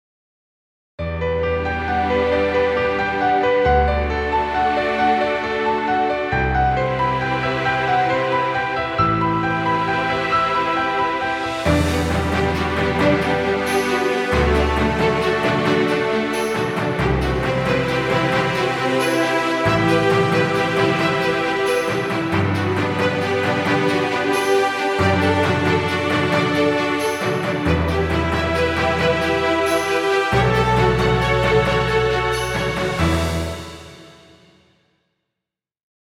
Epic music, exciting intro, or battle scenes.
Background Music.